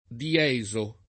diesare v. (mus.); dieso [ di- $@ o ]